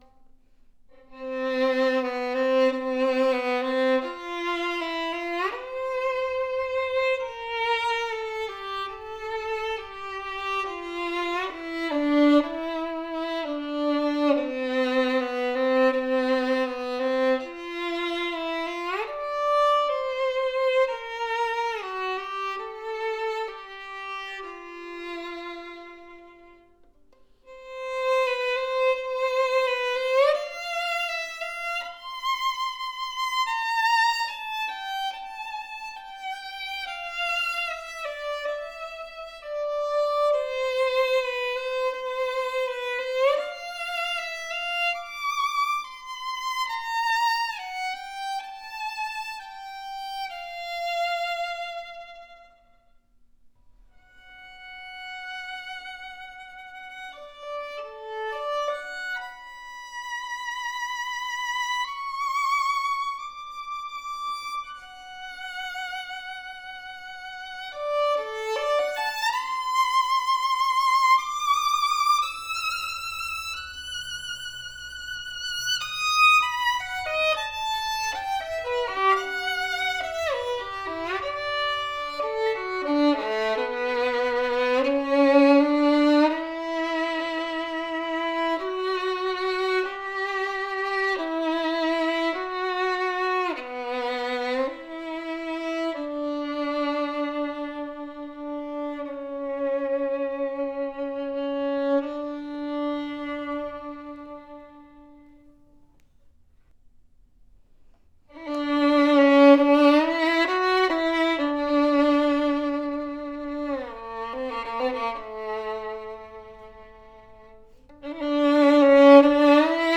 A projective sounding Cannone violin with deep voice, bold lower register, brilliant voice with clear tone! Fast and instant response, very manageable and easy in shifting and handling, best playability. Full resonant G string as the typical Guarneri voice, open voice with a booming texture. Sweet even mid range. Brilliant rounded E string as the performance represents. A powerful sounding violin that will satisfy serious players at very affordable price!